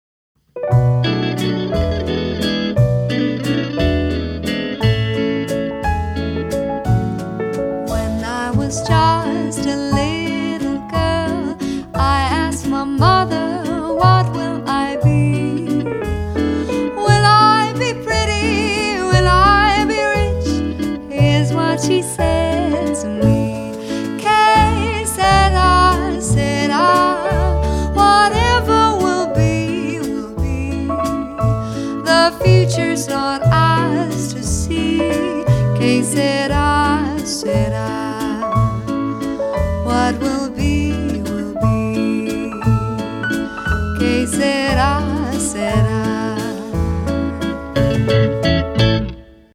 warmen und souligen Stimme
schneller Walzer